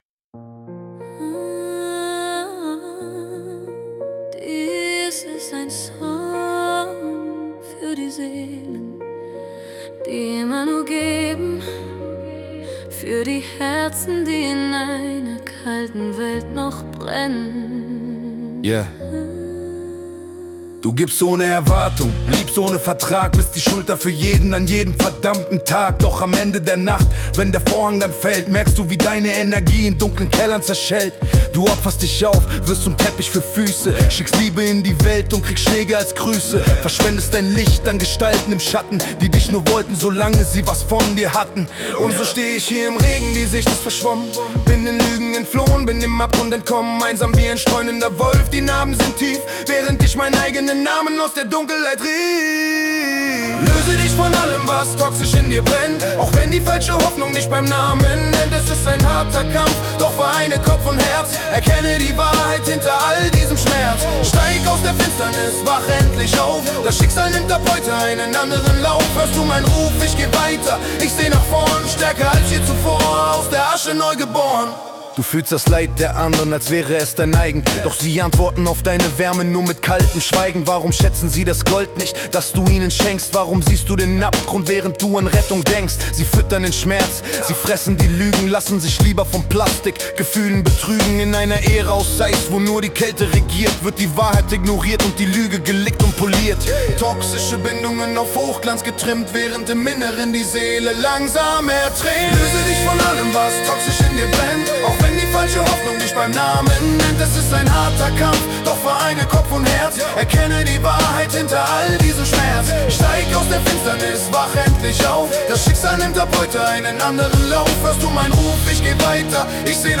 Rap Version